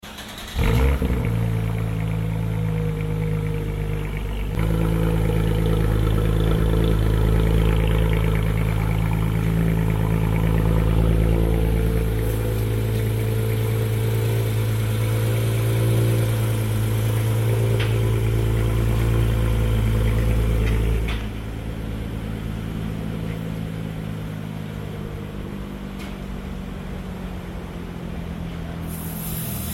My IS350 Sounds So Good Sound Effects Free Download